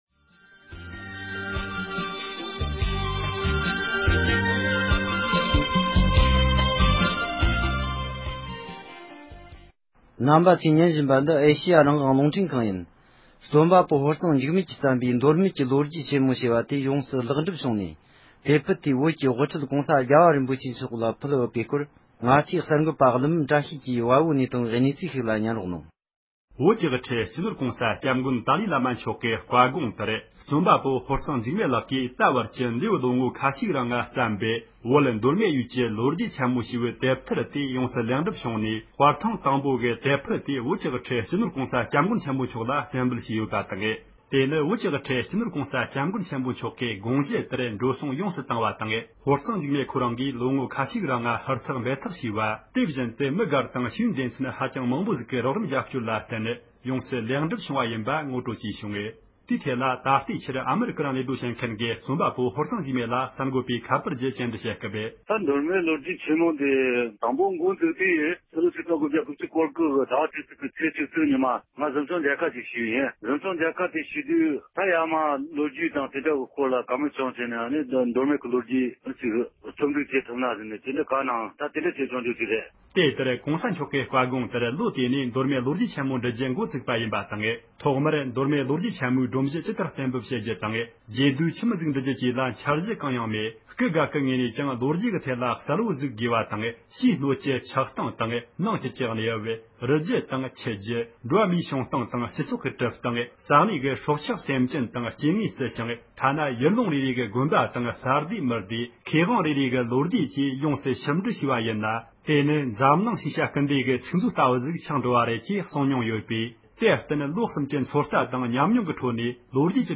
སྒྲ་ལྡན་གསར་འགྱུར། སྒྲ་ཕབ་ལེན།